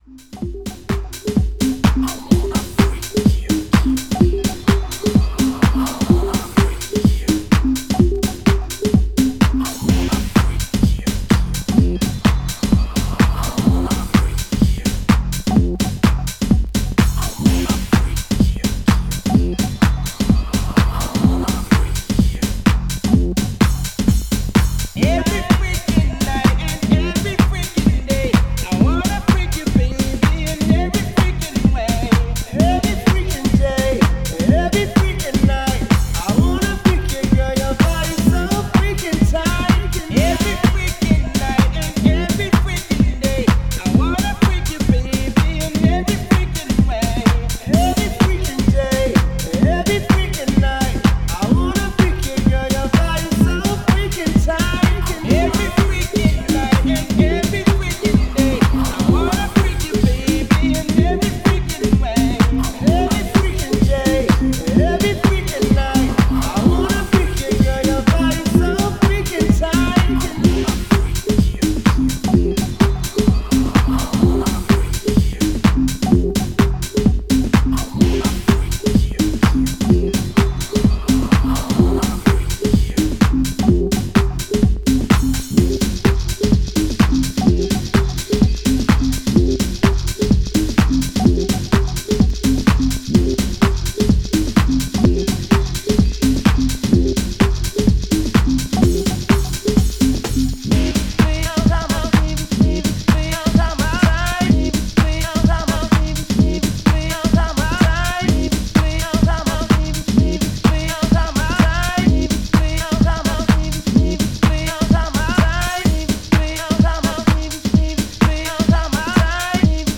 STYLE House / Deep House